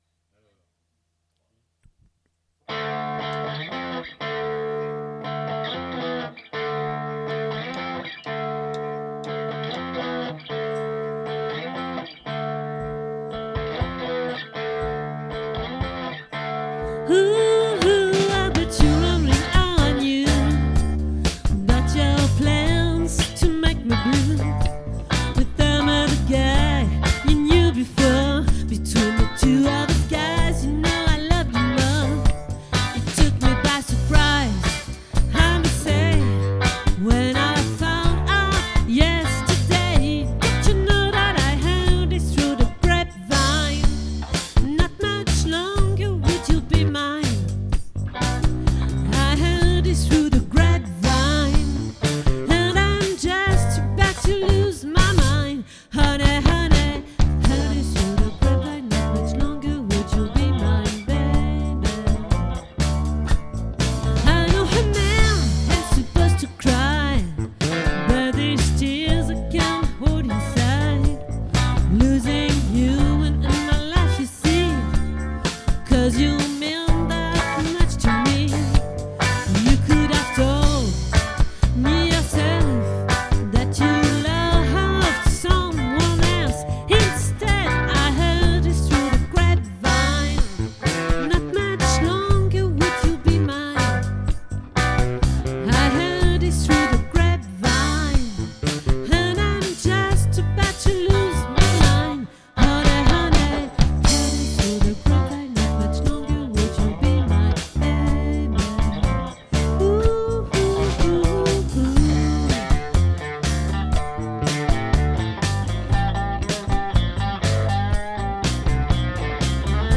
🏠 Accueil Repetitions Records_2025_09_15